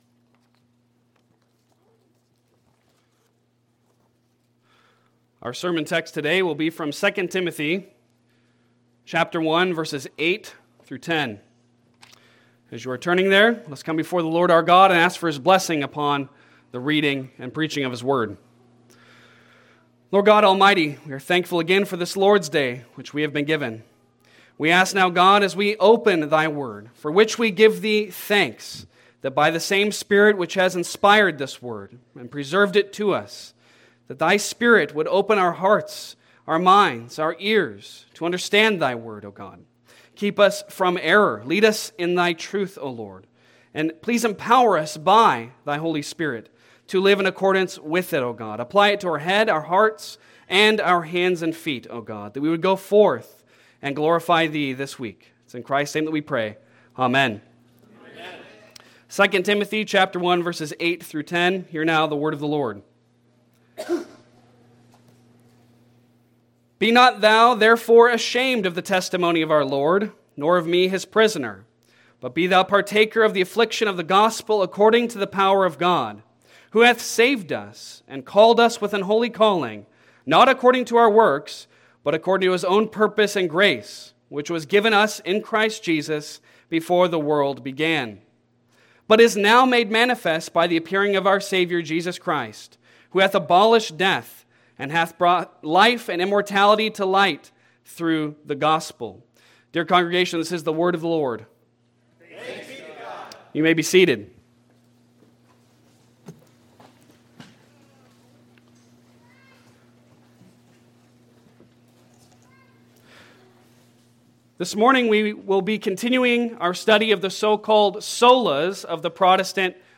Passage: 2 Timothy 1:8-10 Service Type: Sunday Sermon Download Files Bulletin « Sola Scriptura